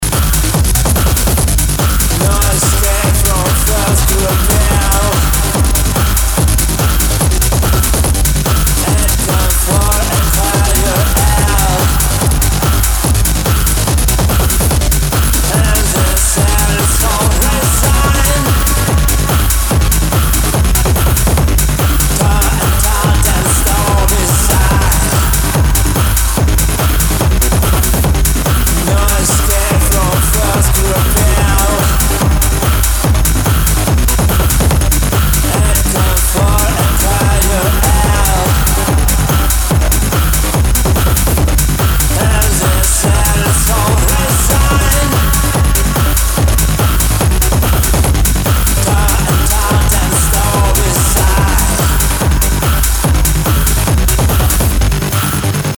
я там обозначил что дисторшн на гитаре, в нём задача -сделать его чтобы вроде он как бы и был, но и чтобы голос через него пробился)
Hard Techno